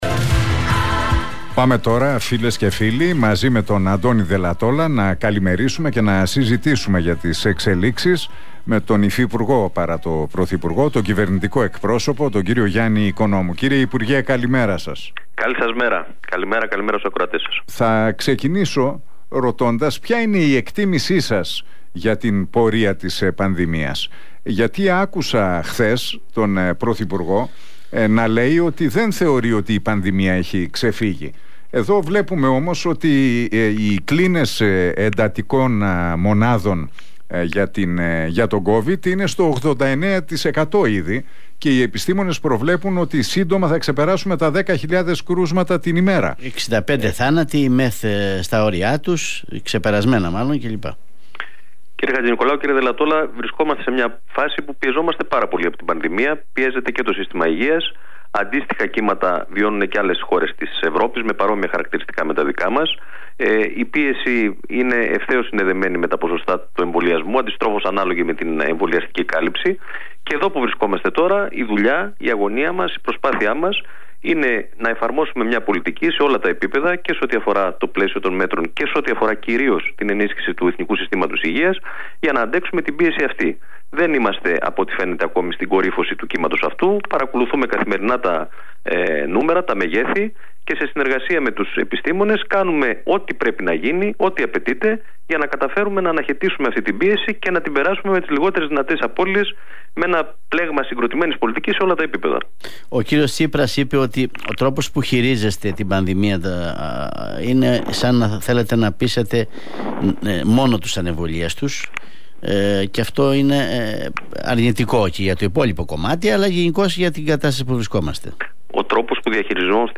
Ο Κυβερνητικός Εκπρόσωπος, Γιάννης Οικονόμου, δήλωσε στον Realfm 97,8 ότι δεν είμαστε ακόμα στην κορύφωση του κύματος αυτού της πανδημίας, ενώ σημείωσε ότι είμαστε κοντά στην επιστράτευση ιδιωτών γιατρών για να ενισχυθεί το ΕΣΥ.